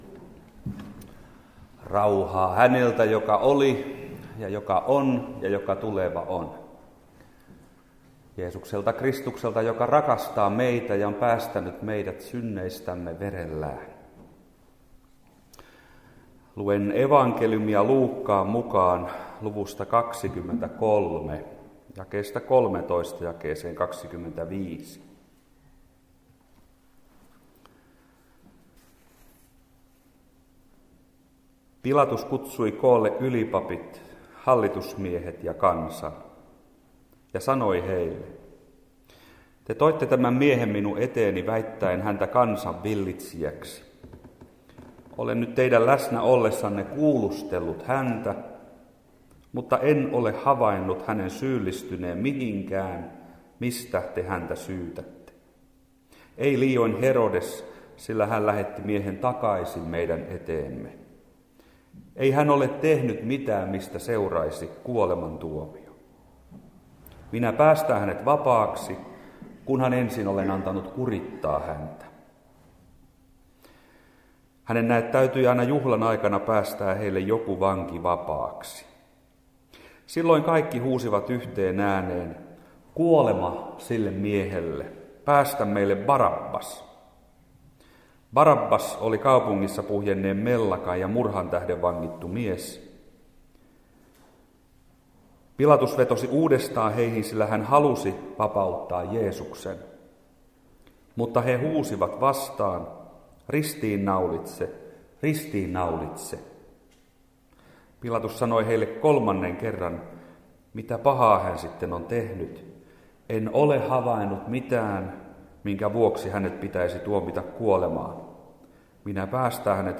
Puhe Ristinjuhlassa Koskenkorvan seurakuntatalolla 22.4.2011 klo 13. Alussa kuultu lukukappale: Luuk. 23: 13-25.
Ristinjuhla_Koskenkorvalla_2011.mp3